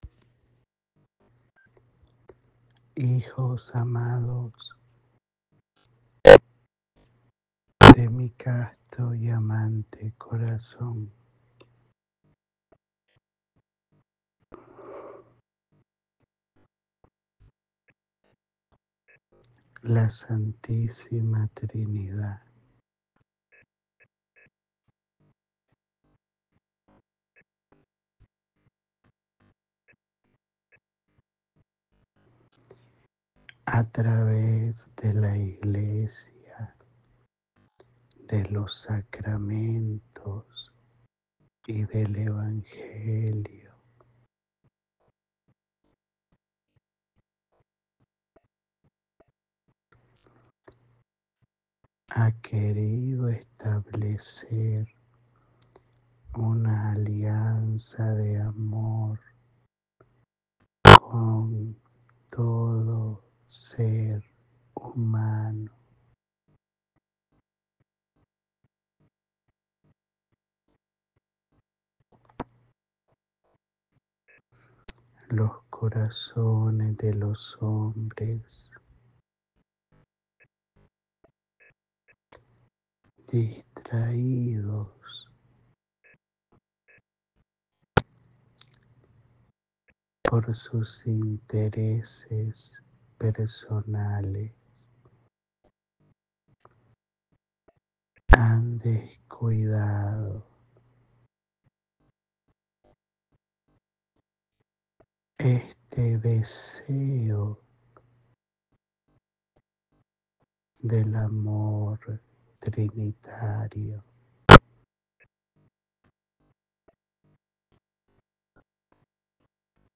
Áudio da Mensagem